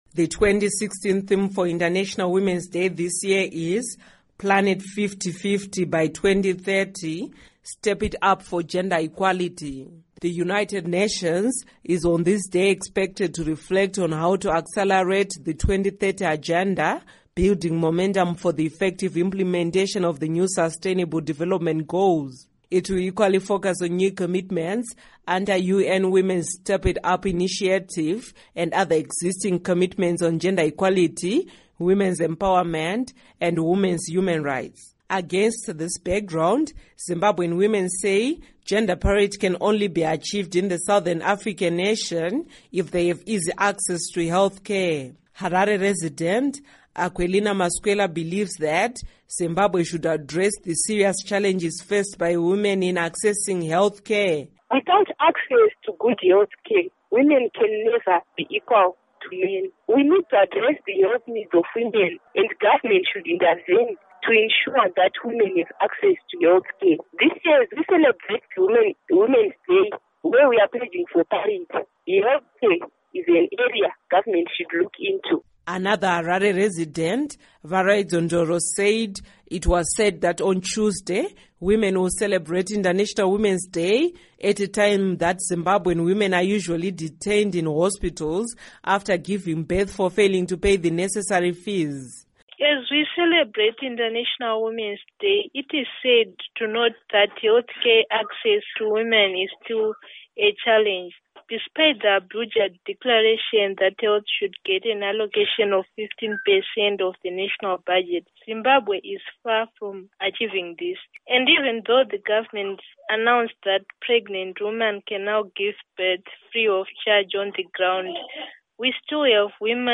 Report on Women's Health